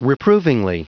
Prononciation du mot reprovingly en anglais (fichier audio)
reprovingly.wav